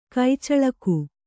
♪ kai caḷaku